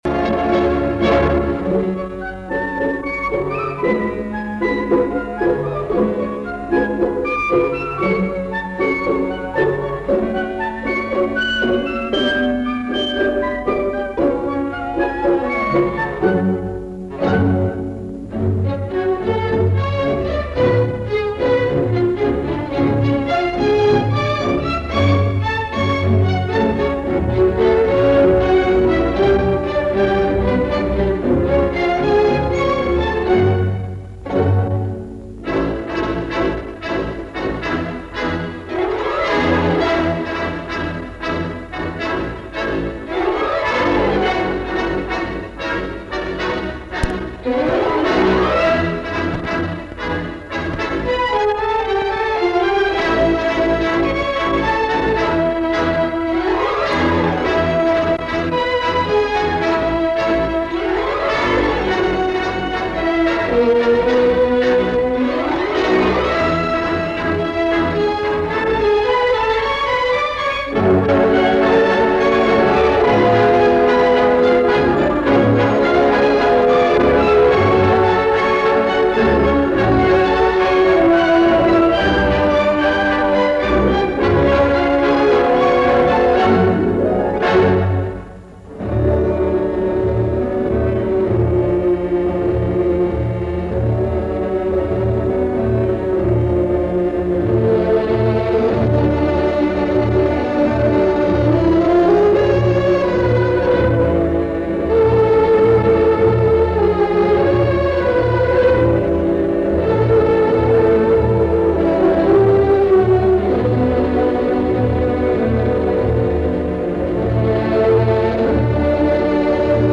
Инструментальная пьеса из оперетты
Фрагмент классической оперетты был записан с эфира в 70е годы.